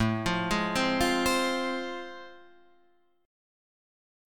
A Minor Major 7th Sharp 5th